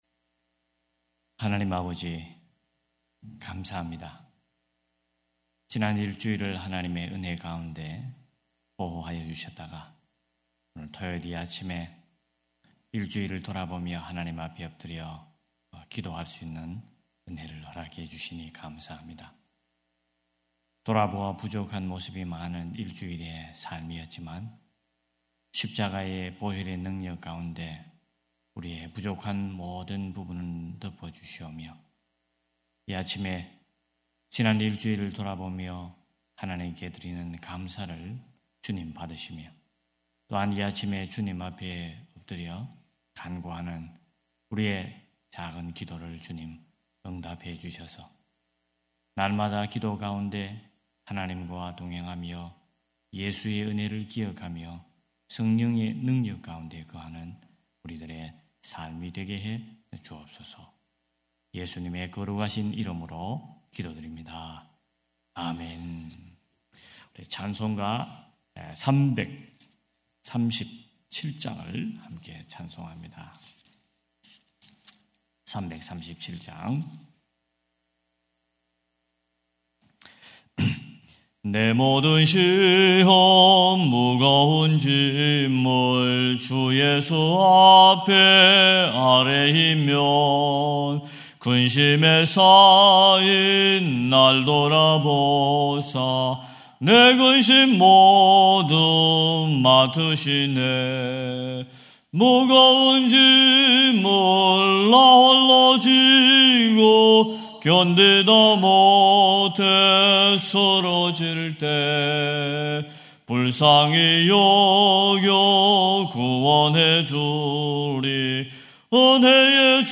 9.20.2025 새벽예배 갈라디아서 6장 11-13절